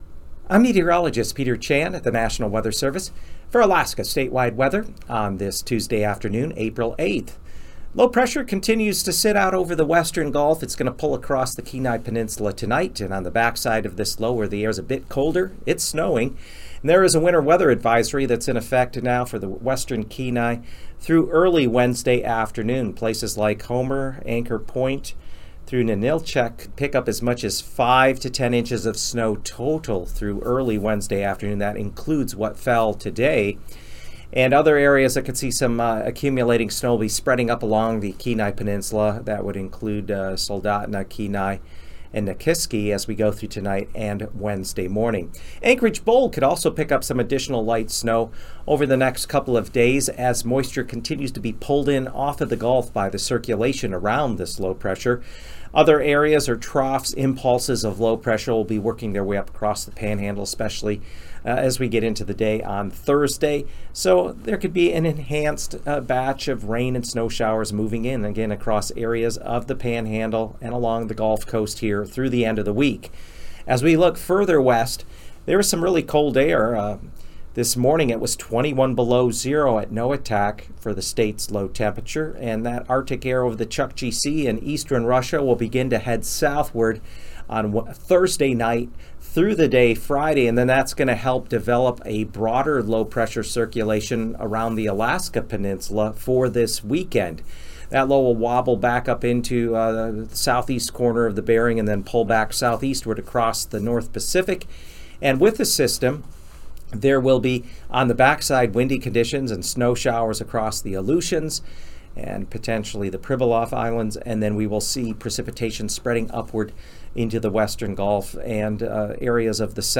Alaska Statewide Weather is produced by meteorologists on the Alaska Weather team.